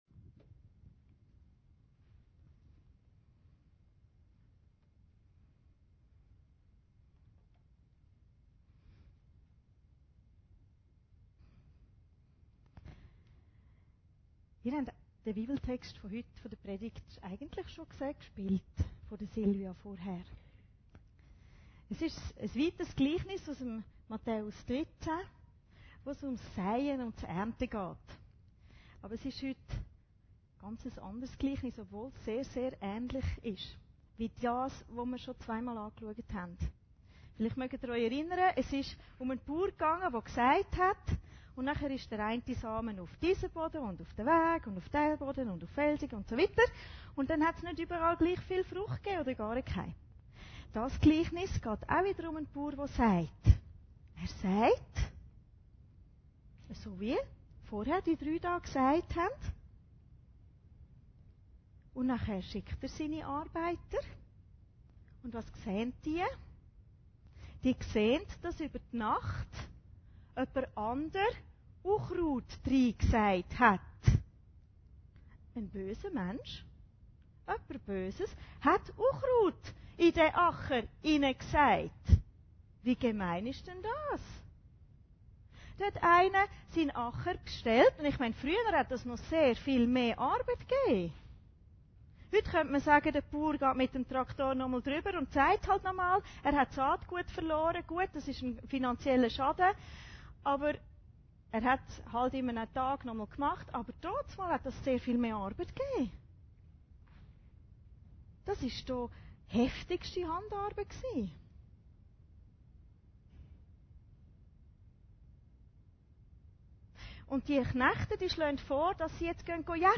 Predigten Heilsarmee Aargau Süd – Gleichnis vom Unkraut